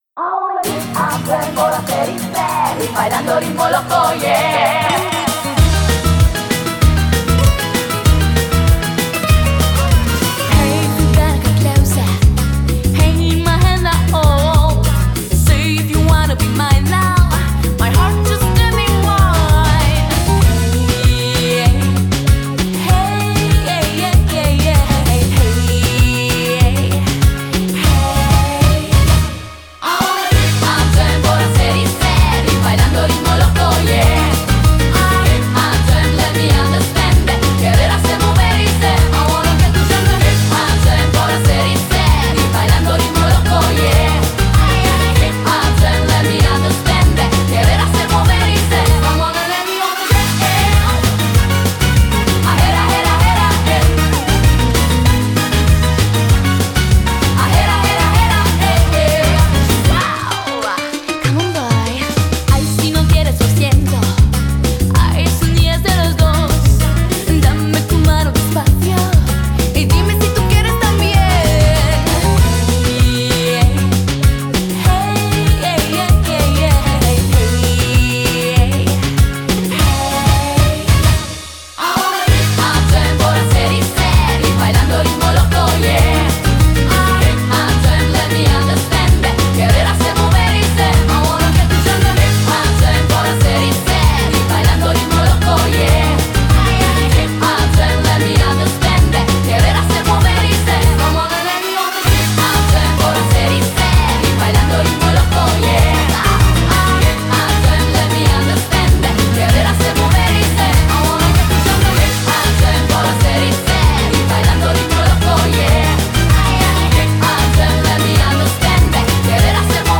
BPM194--1